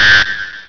Beep1
BEEP1.WAV